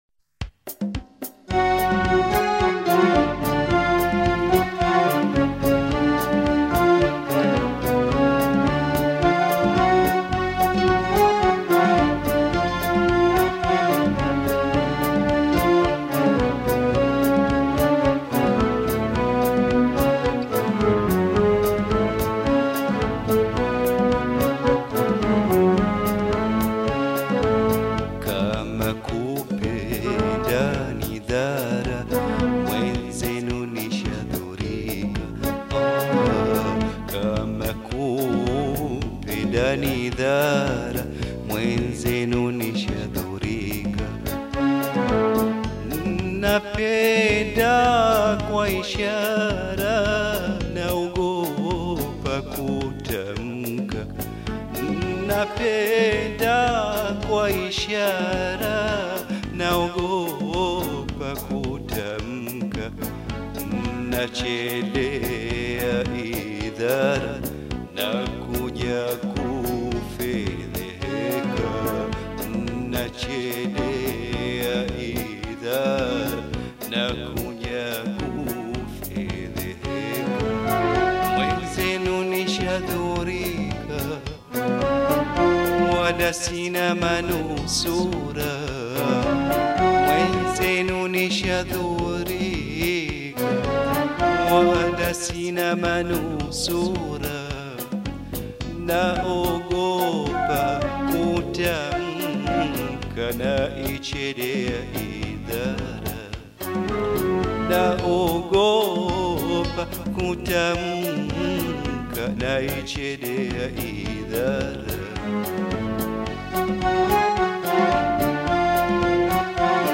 Taarab musician